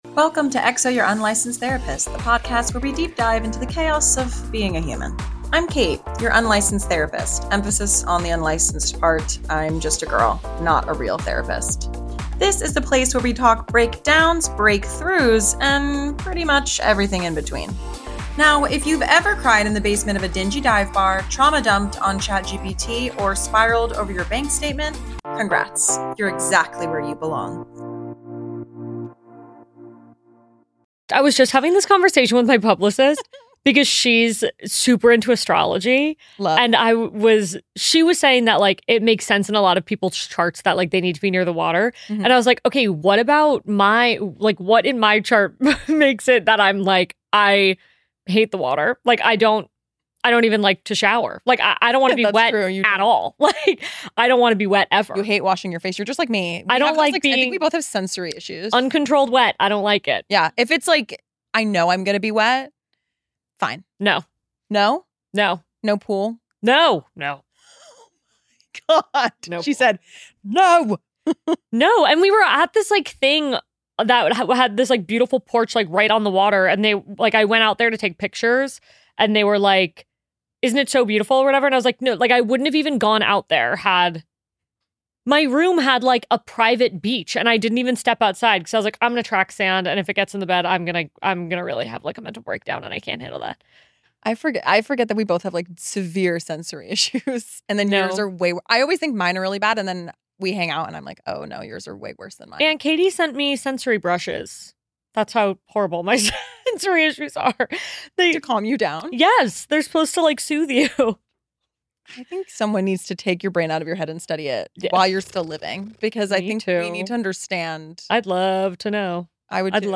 Get an inside look at their friendship and listen to their conversation about growing up in the public eye, slowing down, and choosing peace over perfection.